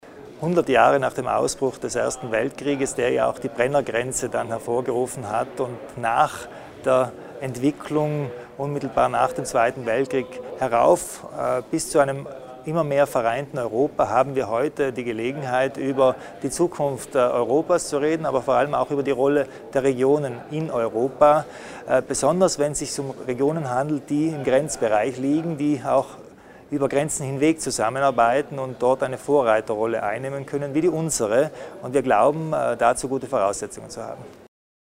Die Zukunft der Regionen in der EU, die Chancen der Europaregion, die Rolle Südtirols und die Verflechtung mehrerer Ebenen bei der politischen Entscheidungsfindung: Auf diese Themen hat Landeshauptmann Arno Kompatscher heute (4. Juli) bei der Eröffnung der Europatagung hingewiesen und diese Themen werden heute und morgen von Wissenschaftlern und Politikern auf Schloss Prösels vertieft.